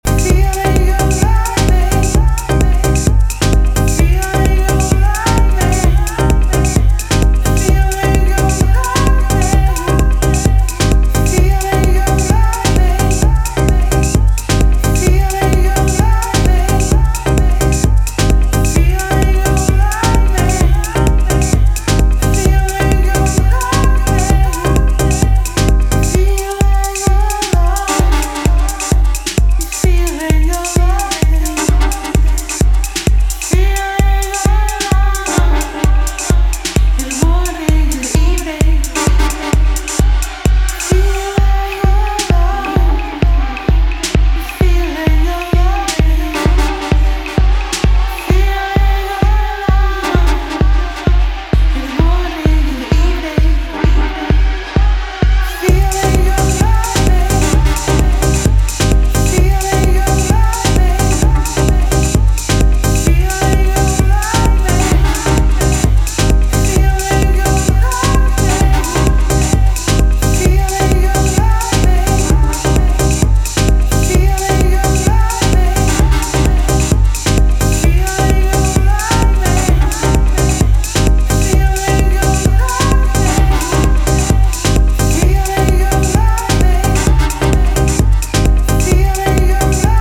愛を歌ったヴォーカルがフロアの熱をキープし続ける中毒性抜群のディープ・ハウス